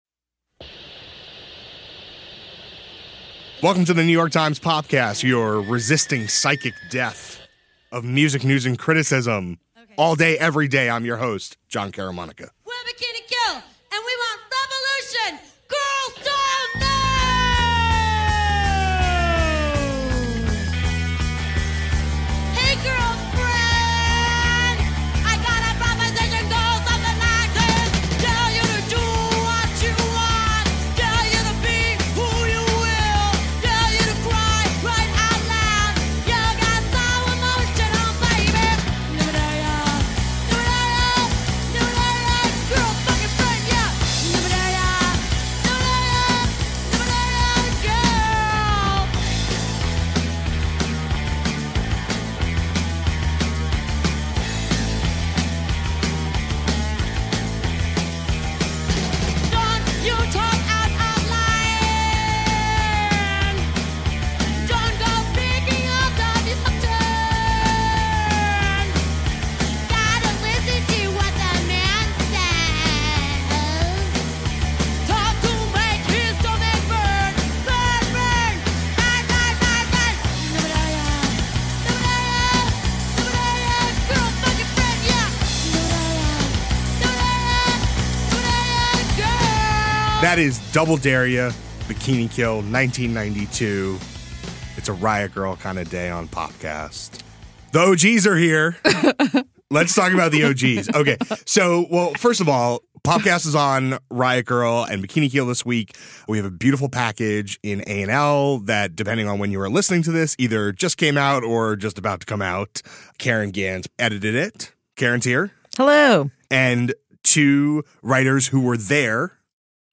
A conversation about riot grrrl and punk-powered feminism.